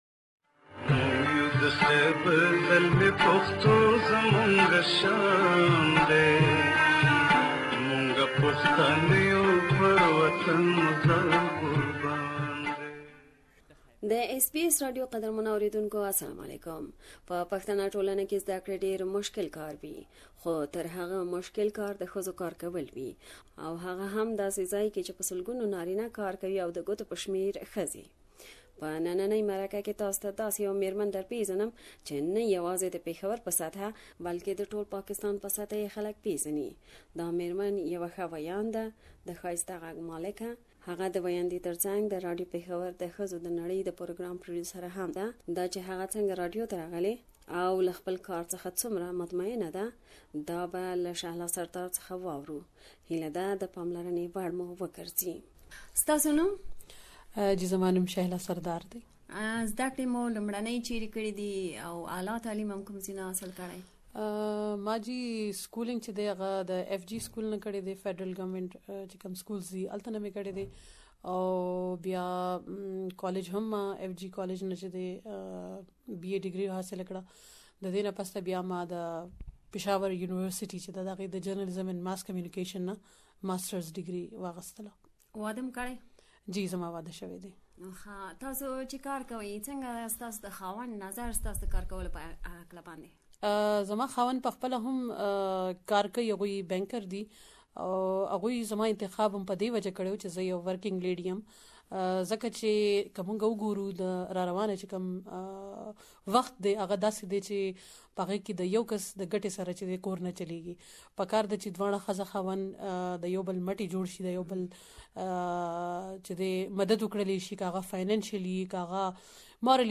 We have asked her about her career journey and achievements and you can listen to her full interview here.